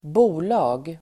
Uttal: [²b'o:la:g]